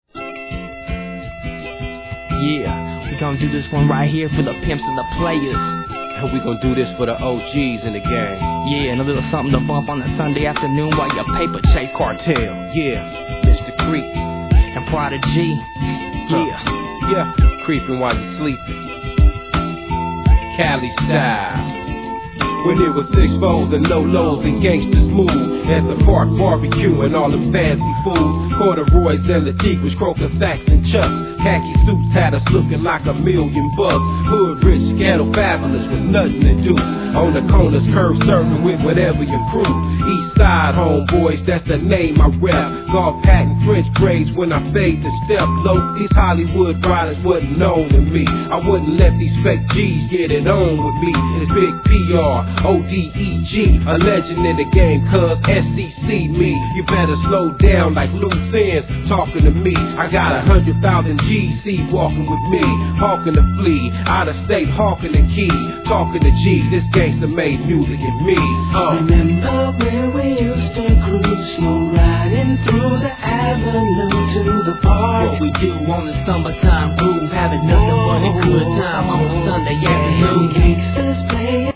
G-RAP/WEST COAST/SOUTH
メロ〜・チカーノ絶品!!